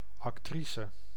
Ääntäminen
Vaihtoehtoiset kirjoitusmuodot (vanhentunut) actour Synonyymit doer plaintiff role actress participant performer player complainant Ääntäminen US UK : IPA : /ˈæk.tə/ US : IPA : /ˈæk.təɹ/ Lyhenteet ja supistumat act.